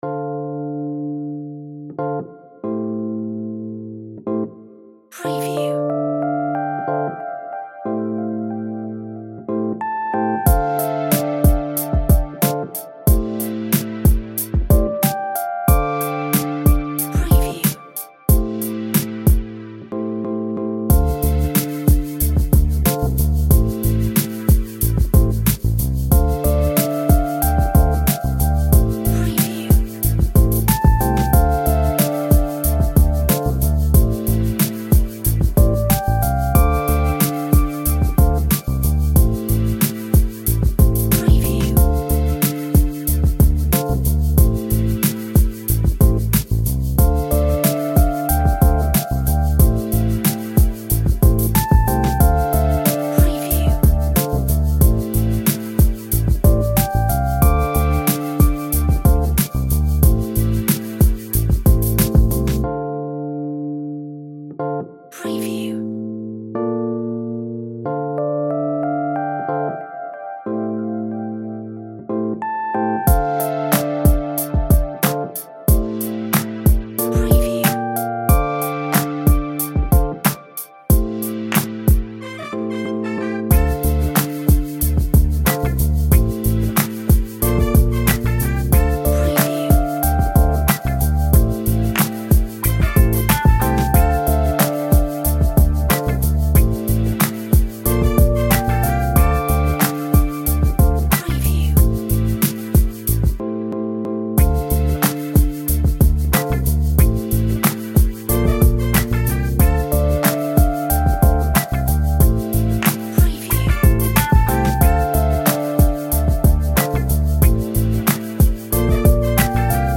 Simple background chill